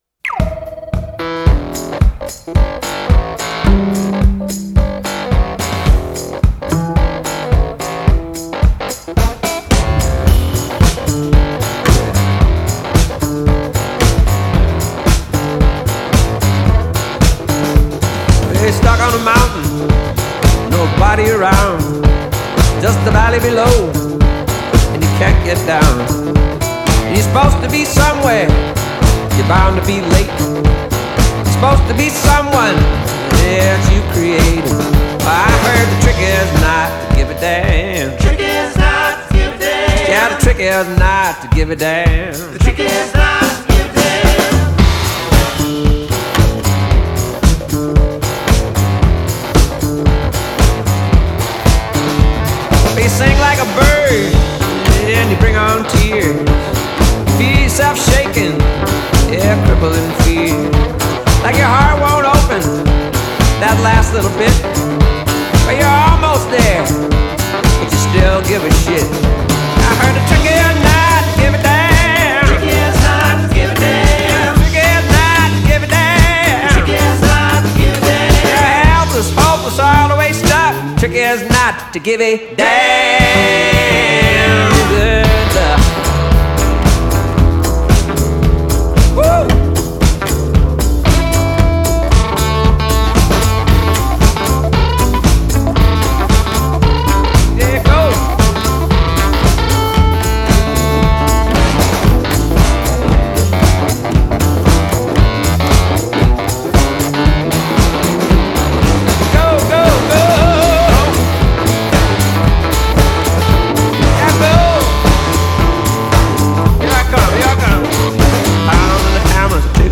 playing a drum cover